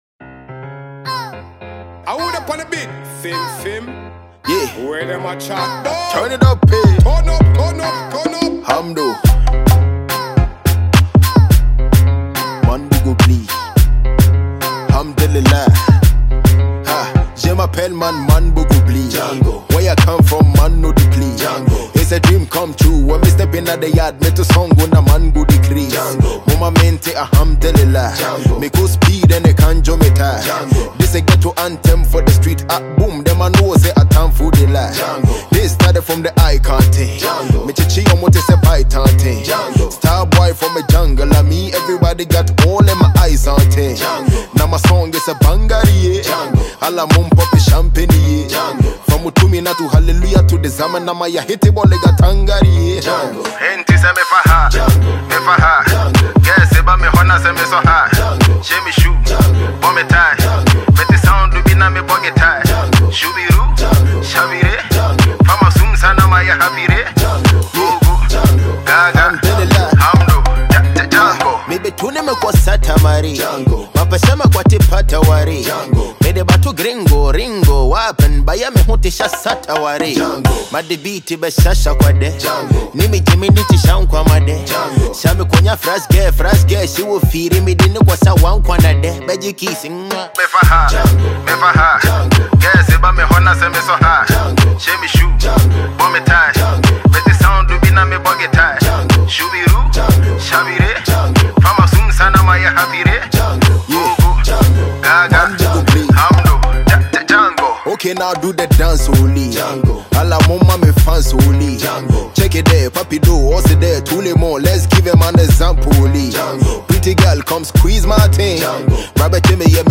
Ghanaian talented rapper